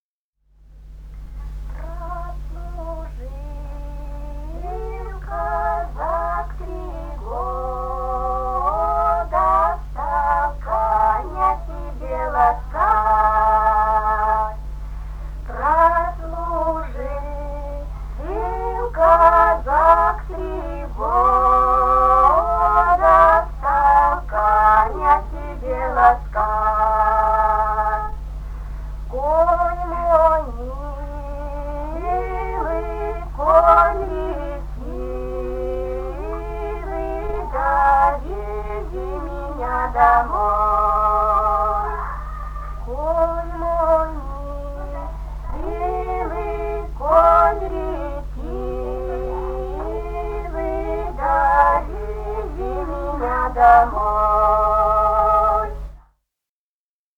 Русские народные песни Красноярского края.
«Прослужил казак три года» (лирическая). с. Тасеево Тасеевского района.